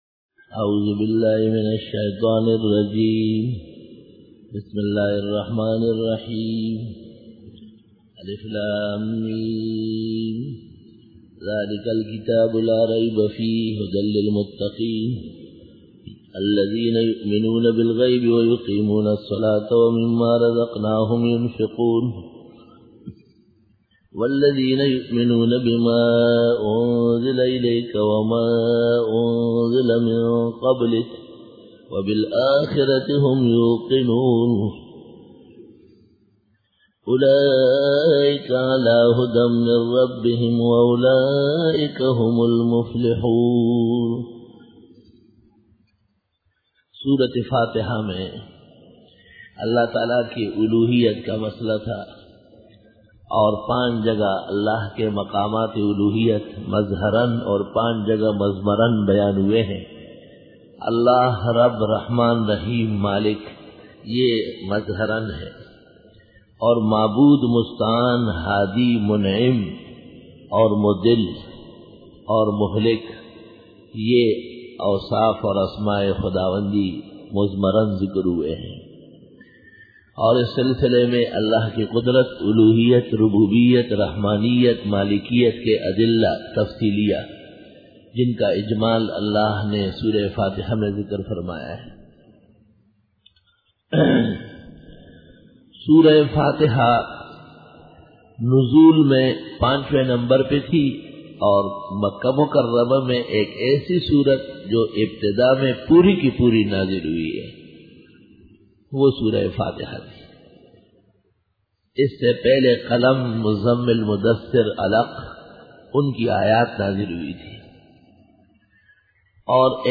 Dora-e-Tafseer 2004